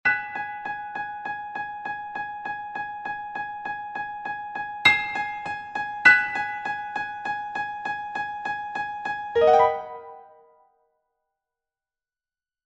Professional-level Piano Exam Practice Materials.
• Vocal metronome and beats counting